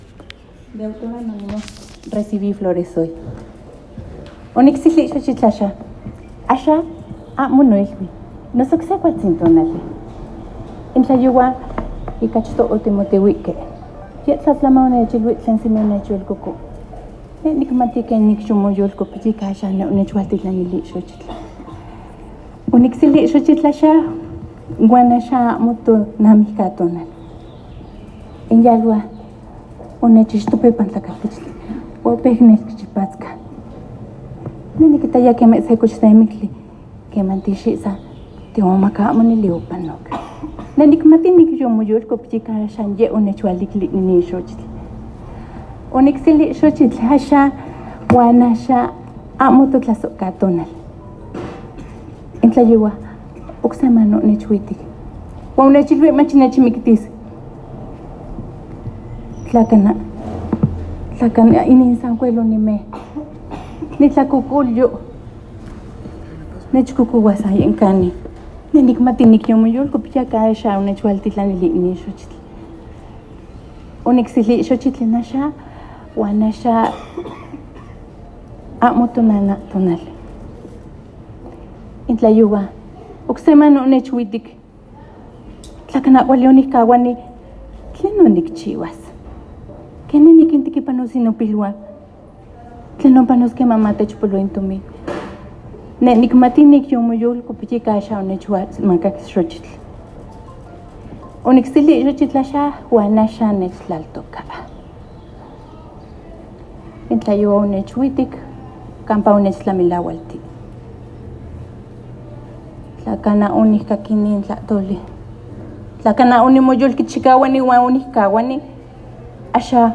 Con motivo del Día Internacional de la Mujer, también se presentaron los paneles: casos de éxito mujeres empoderadas, la procuración e impartición de justicia con perspectiva de género y los derechos humanos de las mujeres.
Audio-Declamación-Hoy-recibí-flores.m4a